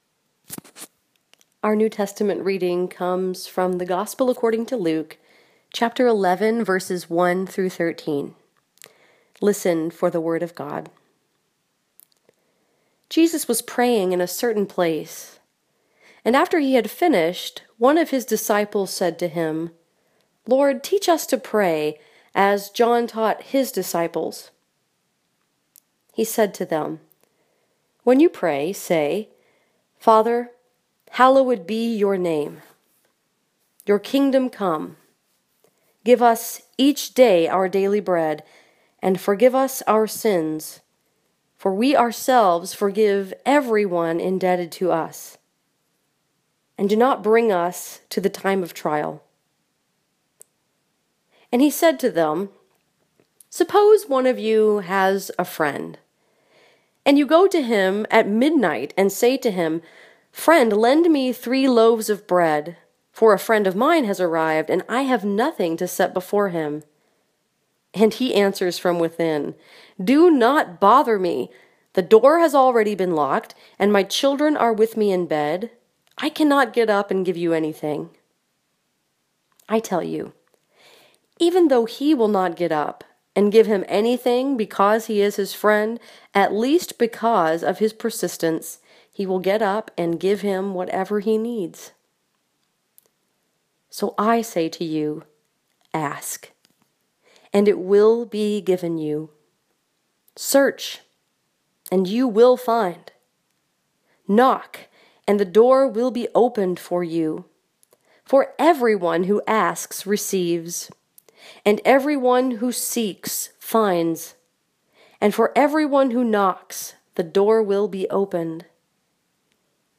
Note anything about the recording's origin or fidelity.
This sermon was preached at St. Andrew’s Presbyterian Church in Dearborn Heights, Michigan and was focused upon Luke 11:1-13.